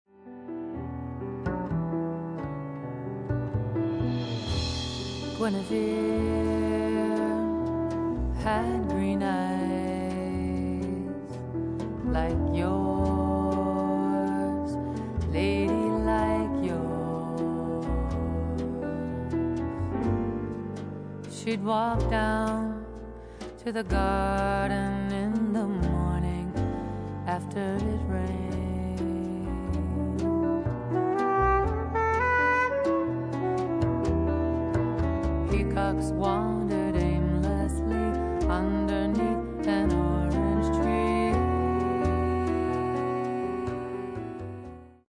vocals
piano
saxes
bass
drums
guitar
and straight-ahead jazz still create musical magic.